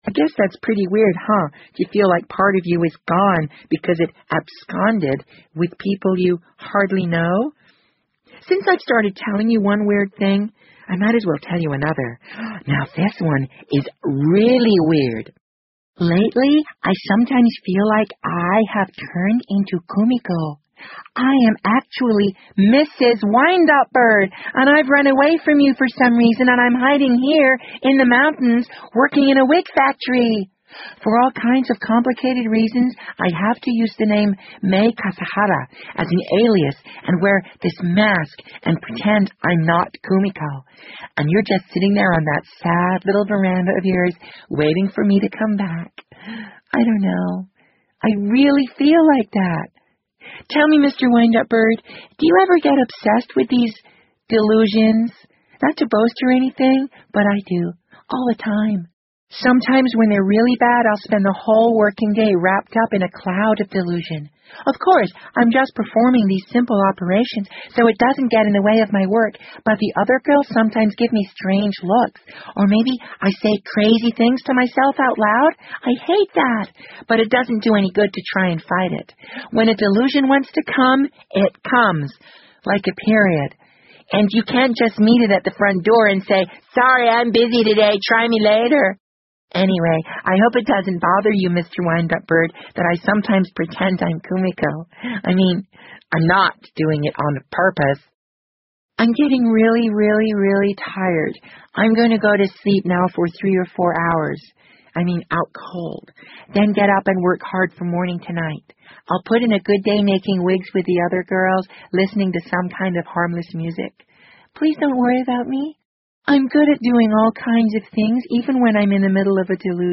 BBC英文广播剧在线听 The Wind Up Bird 013 - 17 听力文件下载—在线英语听力室